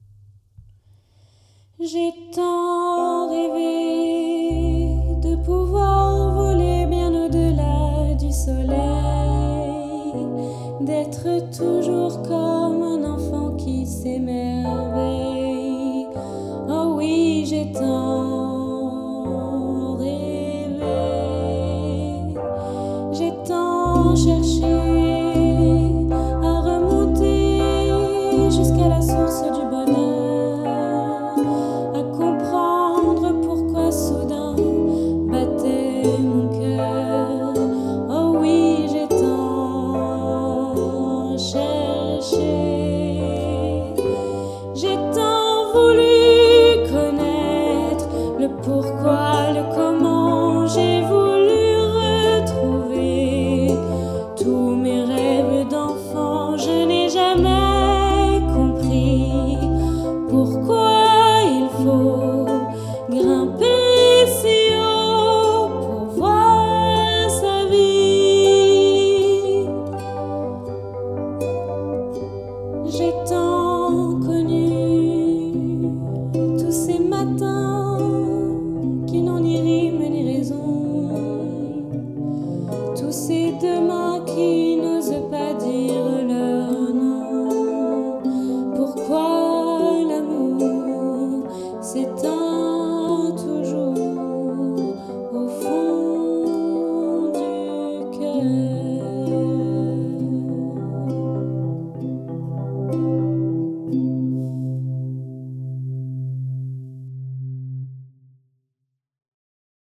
Chanteuse
17 - 35 ans - Soprano